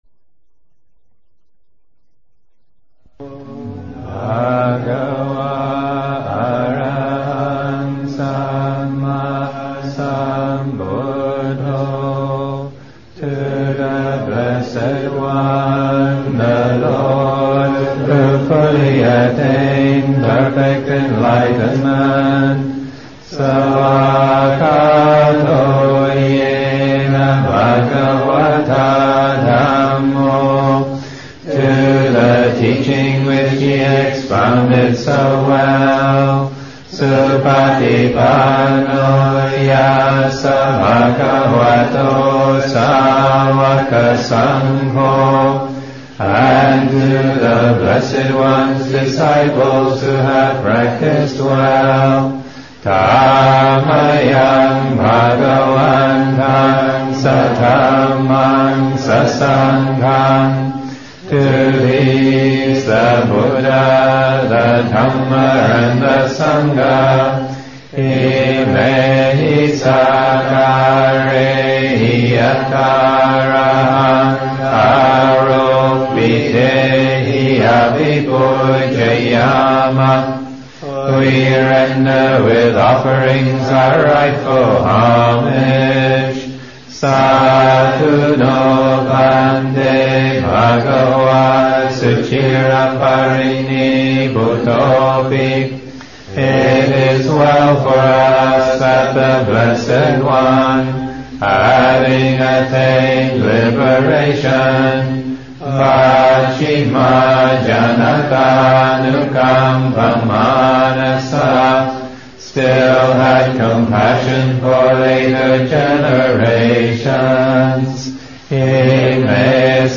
Chanting: Morning Chanting